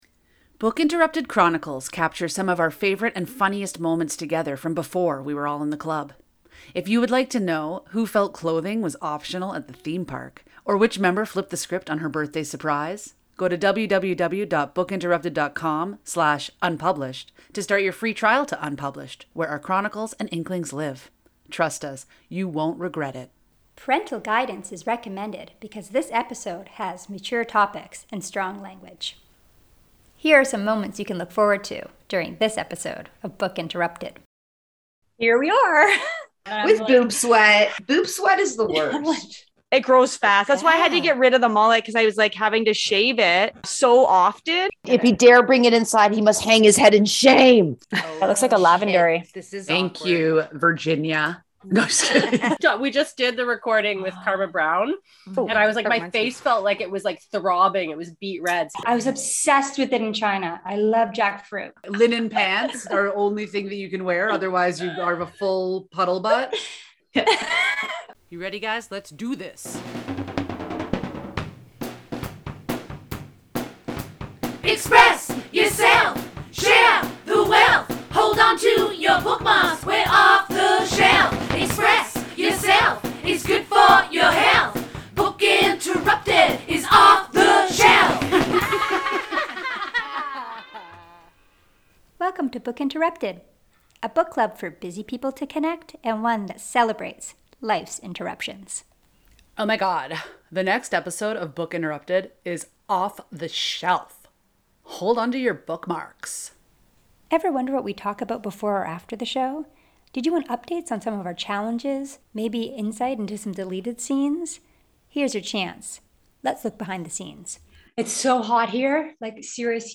During this episode we take a glimpse behind the scenes while the Book Interrupted women discuss dealing with the heat, styling hair and stinky fruit.